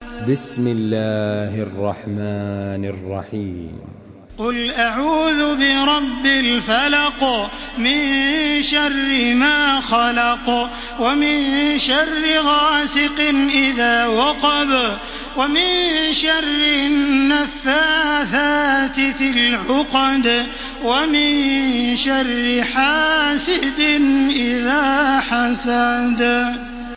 تحميل سورة الفلق بصوت تراويح الحرم المكي 1433
مرتل